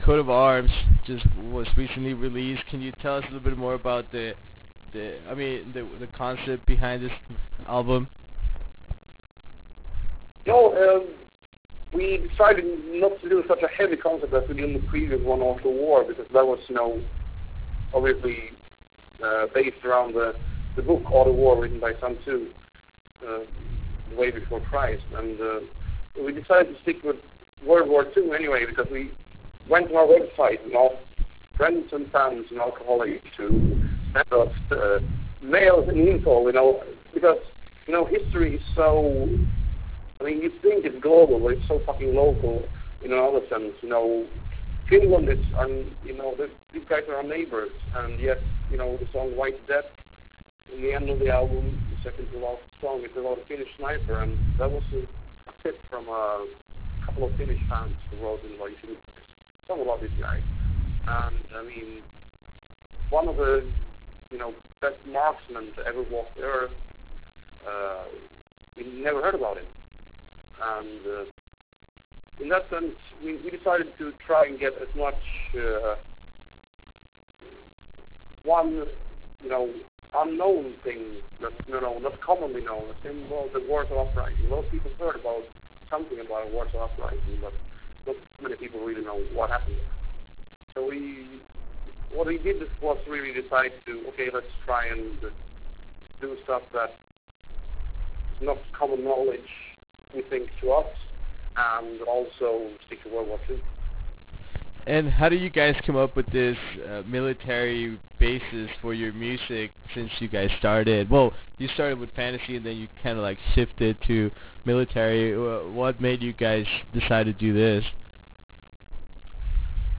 We managed to sneak a conversation with the voice behind this great band, Joakim Broden. We started off this interview discussing the lyrical ideas and concept behind “Coat of Arms”. We also talked about whiny musicans and future plans for Sabaton, and how Joakim ended up being the vocalist of the band.
Interview with Sabaton - Joakim Broden.wav